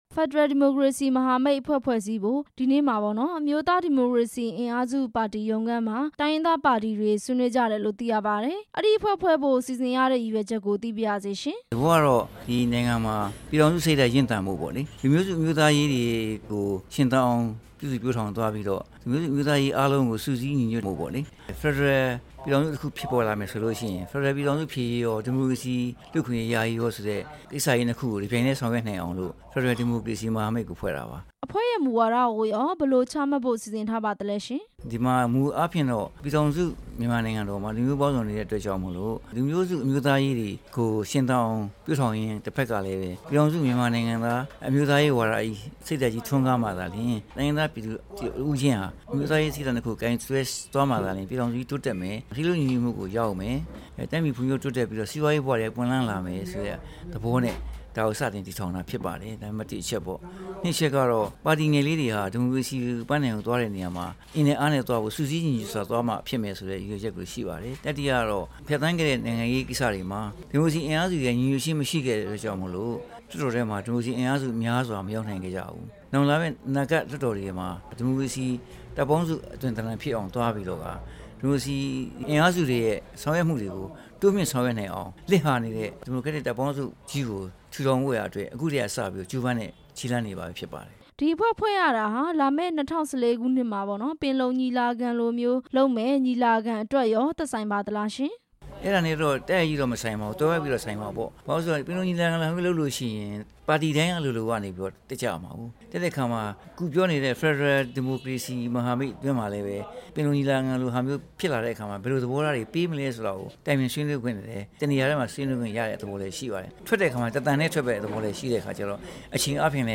NDF ဥက္ကဌ ဦးခင်မောင်ဆွေနဲ့ မေးမြန်းချက်